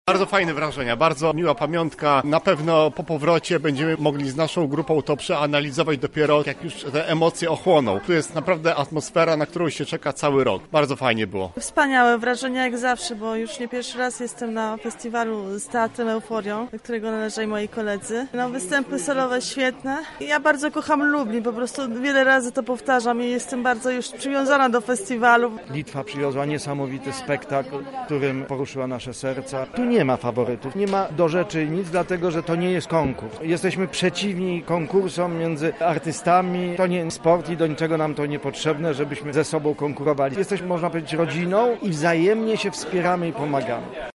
A jak wydarzenie relacjonują organizatorzy i uczestnicy?